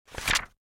Sound Effects
Page Flip